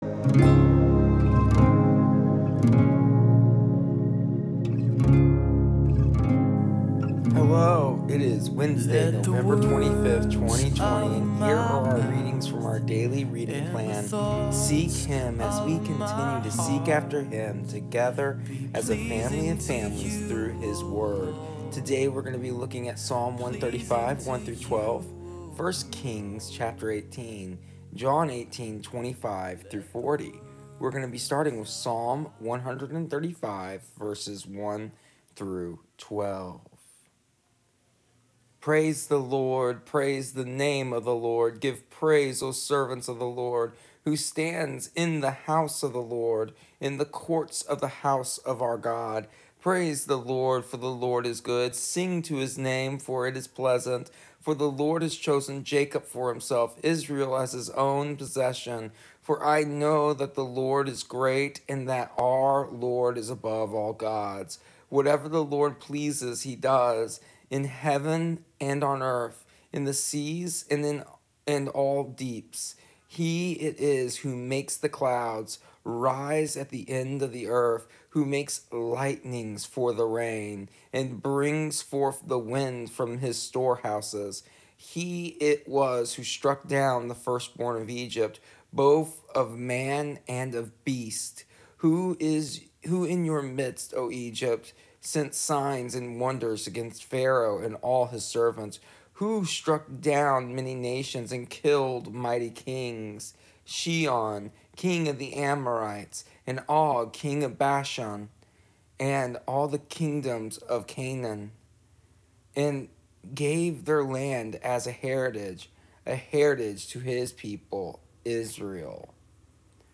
Here is the audio version of our daily readings from our daily reading plan Seek Him for November 25th, 2020. How do hundreds of prophets of a false god stand in comparison to one prophet of the one and only true God?